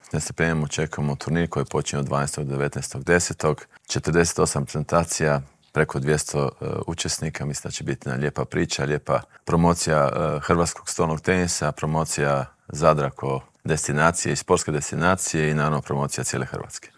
Zadar uskoro postaje središte Europskog stolnog tenisa. U Intervjuu tjedna Media servisa ugostili smo predsjednika Hrvatskog stolnoteniskog saveza Zorana Primorca.